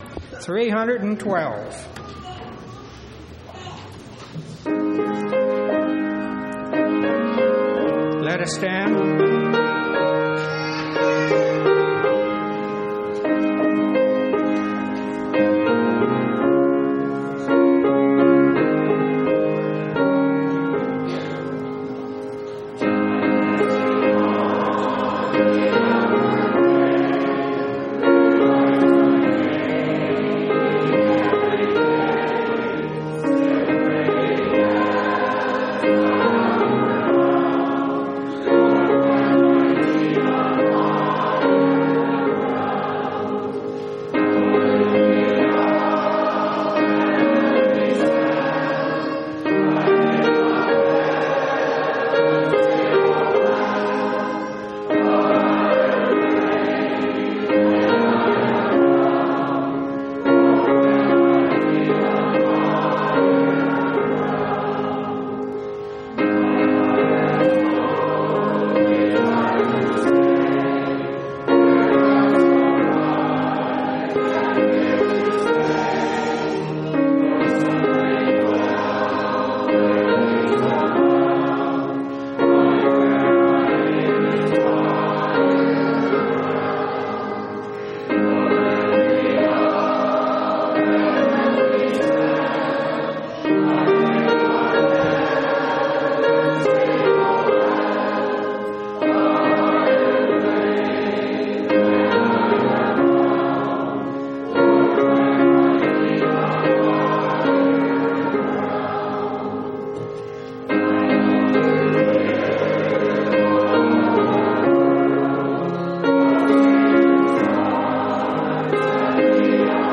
Event: General Church Conference